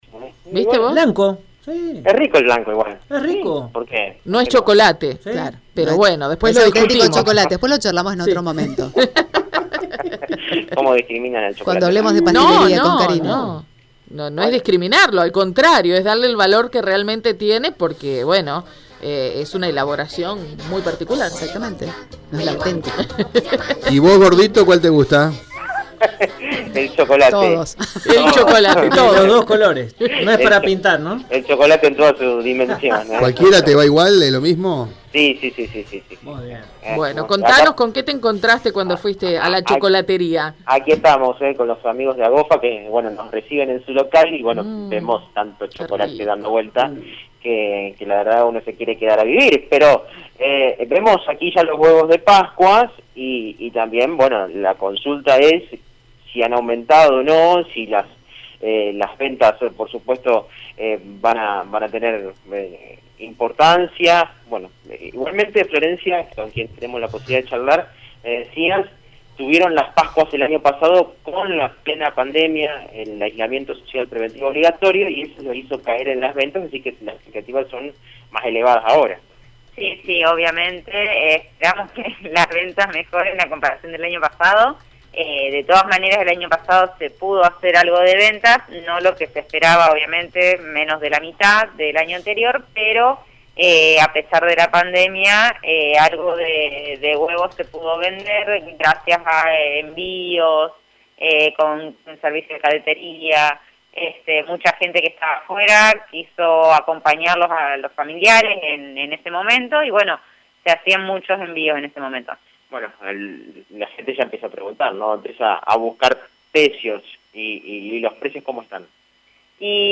AUDIO DESTACADOAudiosSanta Fe